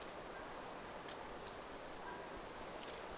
Tarkistin heti, että voisiko olla joutsenhanhi. :)